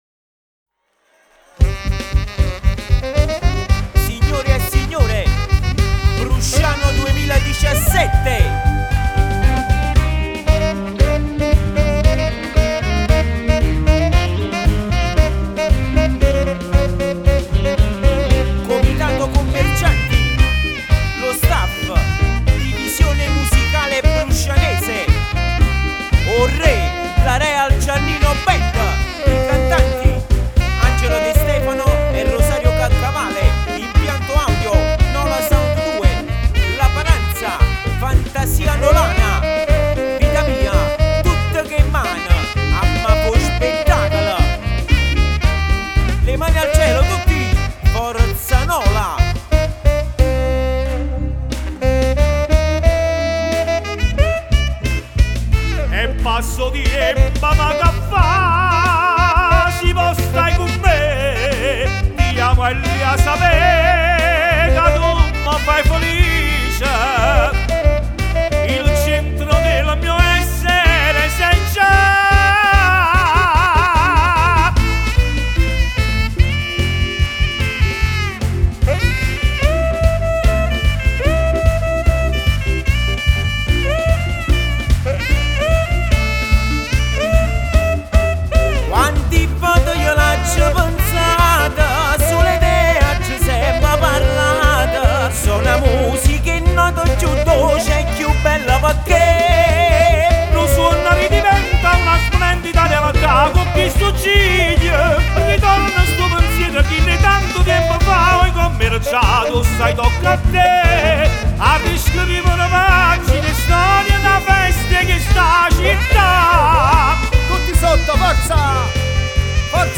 Medley Live